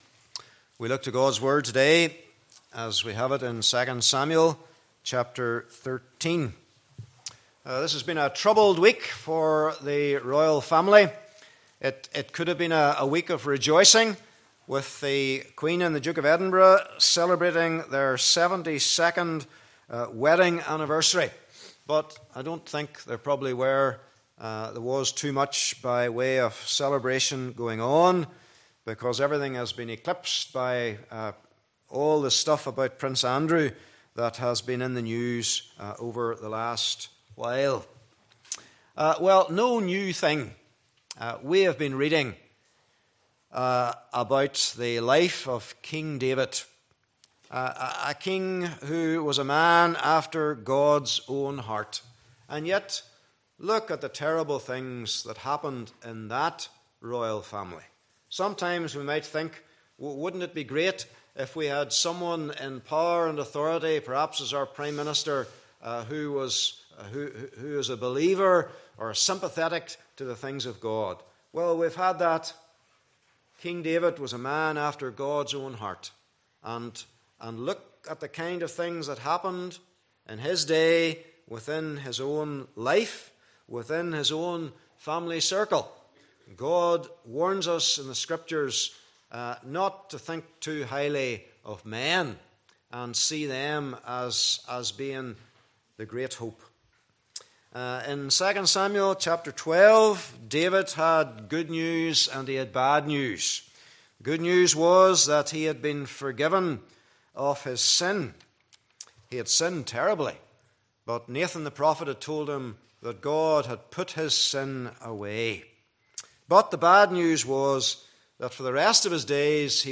Passage: 2 Samuel 13:1-39 Service Type: Morning Service